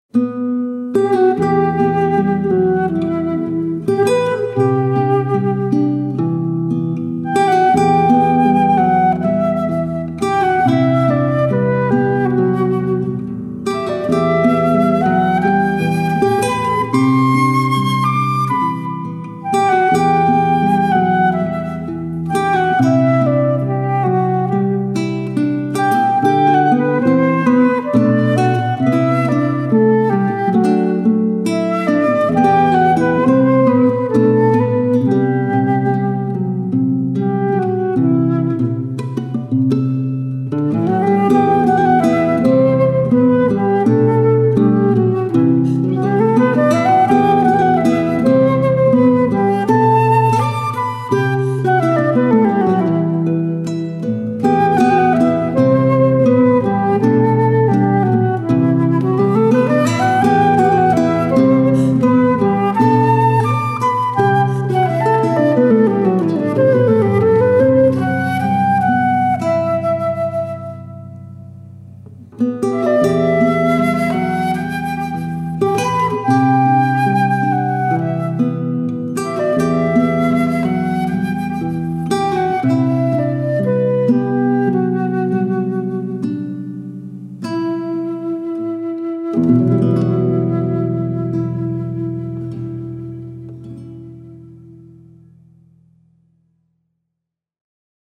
duo acoustique guitare/flûte trés épuré.